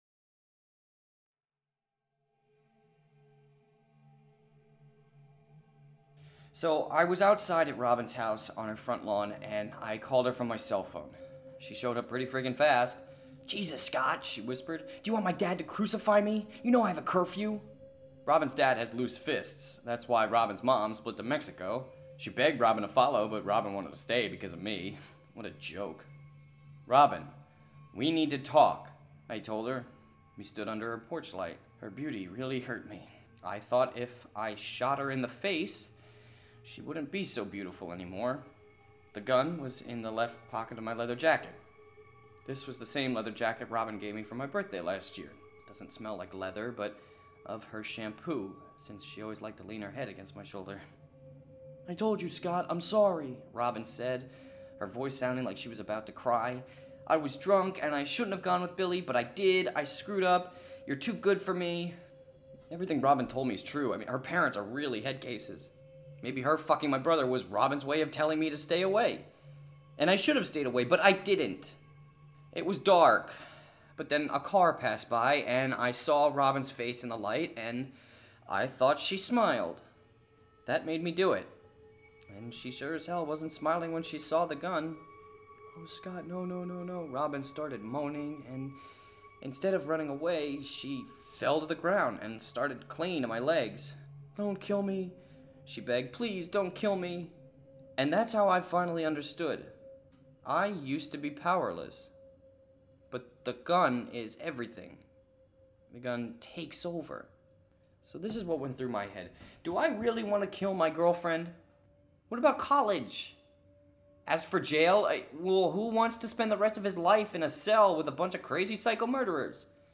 Sound design